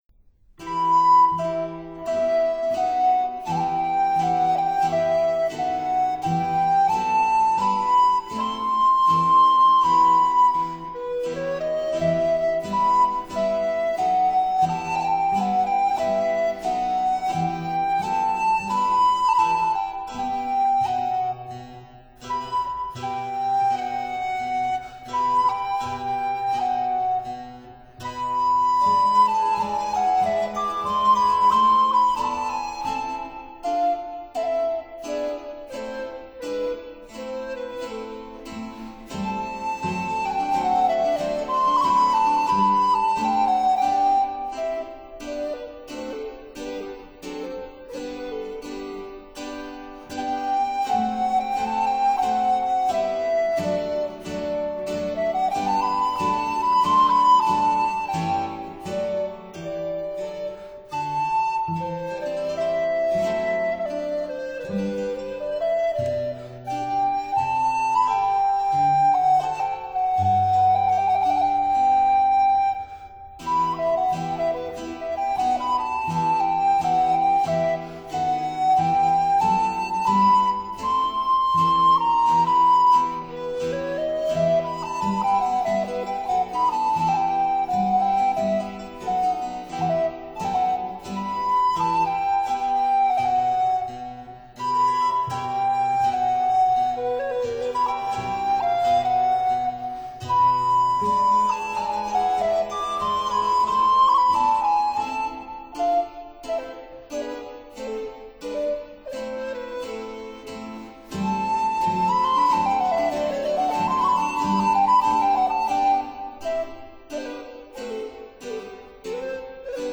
recorders
baroque cello
harpsichord
lute